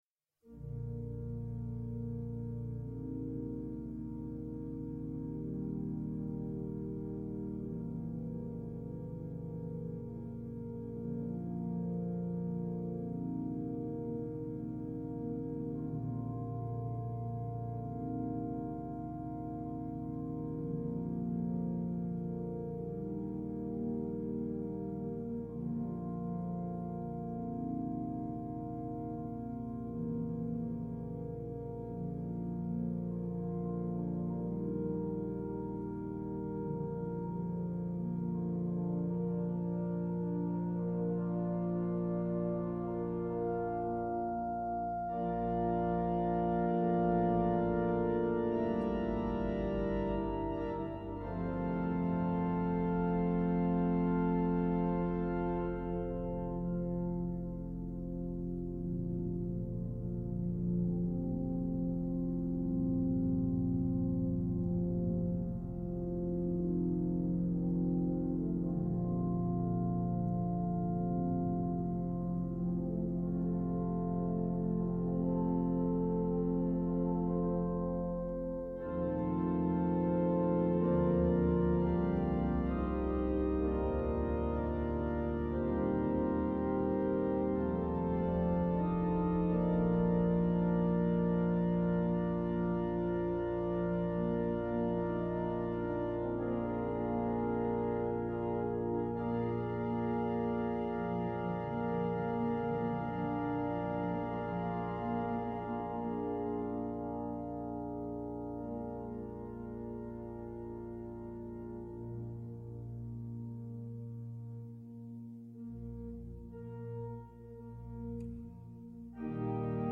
Concierto de Otoño a la Luz de las Velas
on the new Allen Quantum organ at the Cathedral of Valladolidin in Northern Spain.
all live recorded and published on this page.